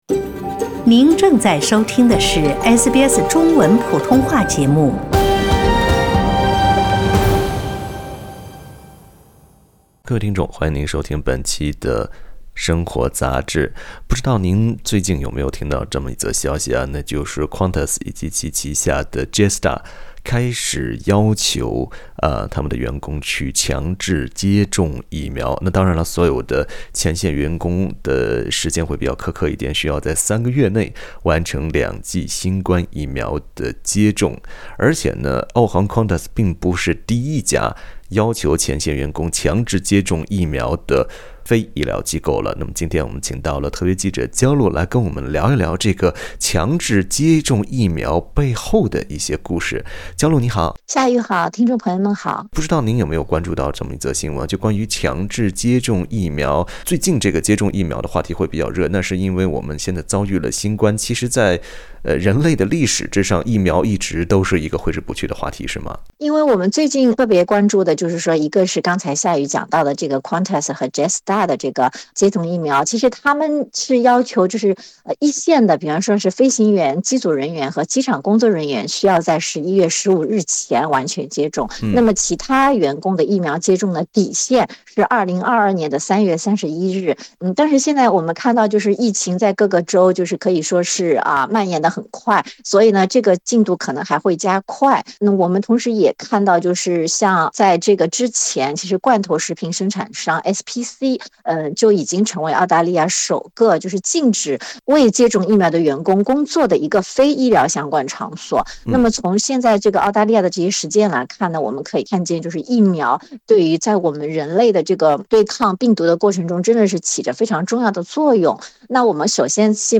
（点击图片音频，收听完整采访）